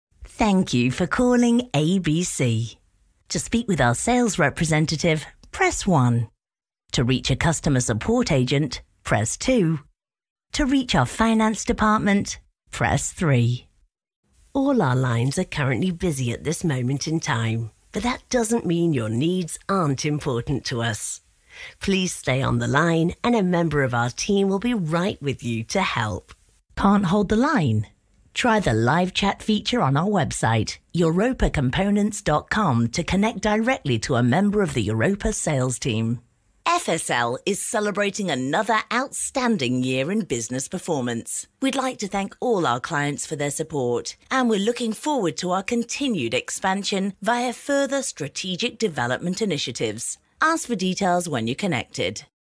Naturelle, Accessible, Chaude, Corporative, Fiable
Téléphonie
Fluide, Naturelle, Riche, Profonde, Sensuelle, Rauque, Nordique, Mancunienne, Manchester, Régionale, Chaleureuse, Assurée, Fiable, Maternisante, Rassurante, Amicale, Accessible, Conversante, Professionnelle, Dynamique, Experte.